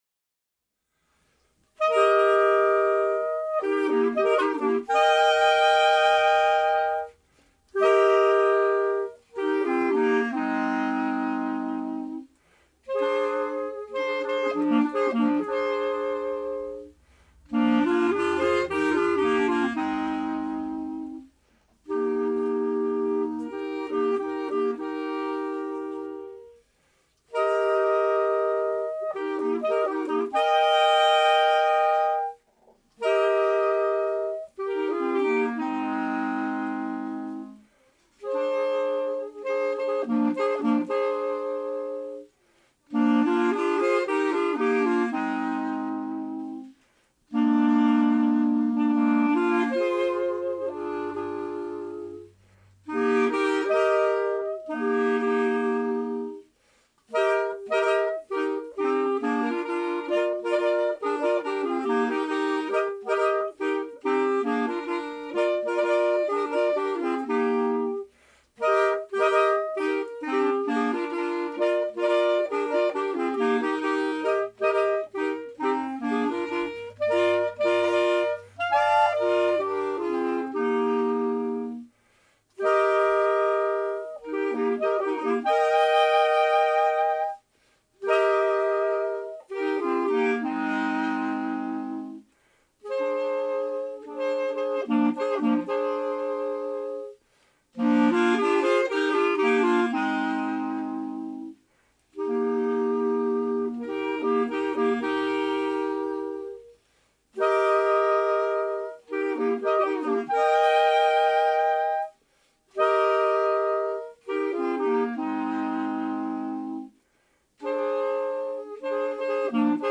Jodler, Jodler-Lied, Gstanzl und Tanz
Folk & traditional music